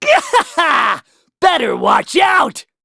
Kibera-Vox_Skill4_b.wav